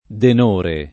[ den 1 re ]